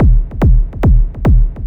K-6 Kick.wav